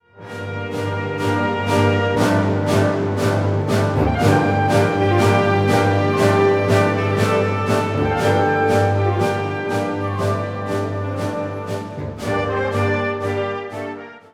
Unterkategorie Konzertmusik
Besetzung Ha (Blasorchester)